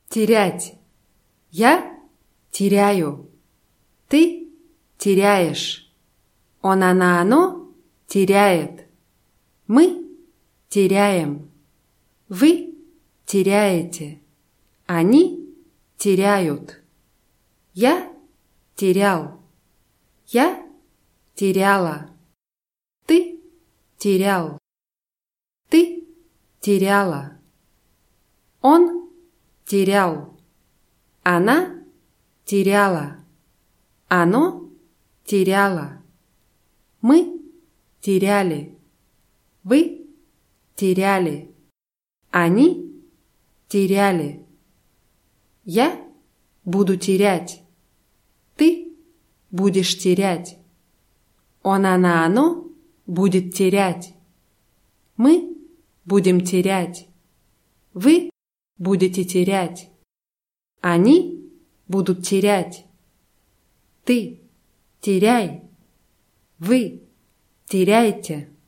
терять [tʲirʲátʲ]